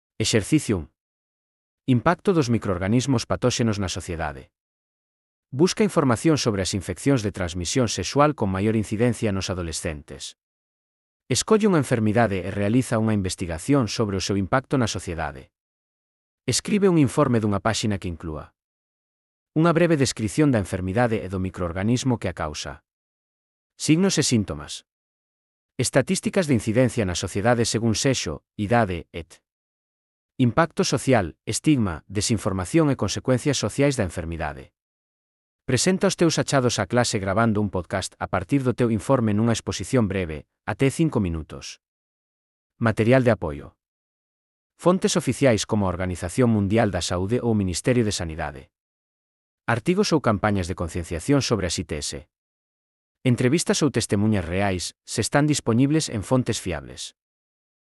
Elaboración propia coa ferramenta Narakeet. Transcrición de texto a audio dos exercicios (CC BY-SA)